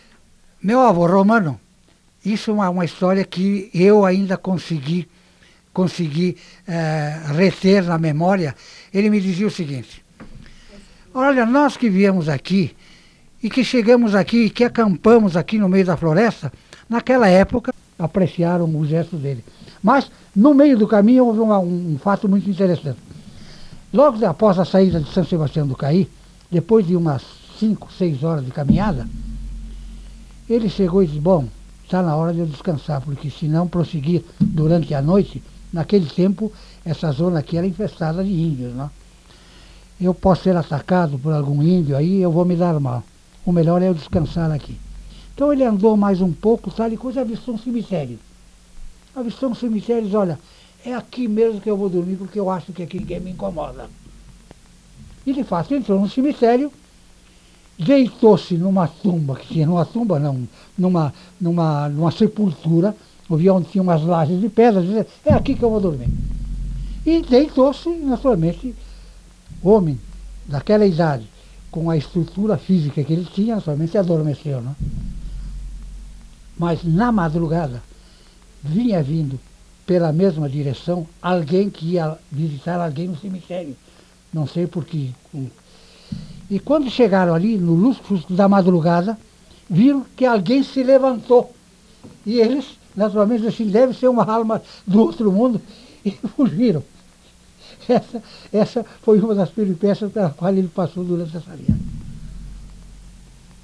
Unidade Banco de Memória Oral